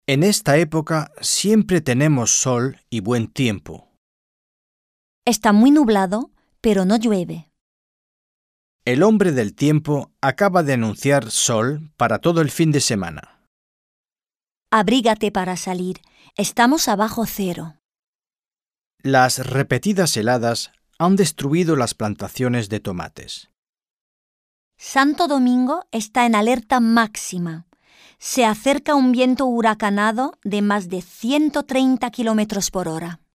Un peu de conversation - Le climat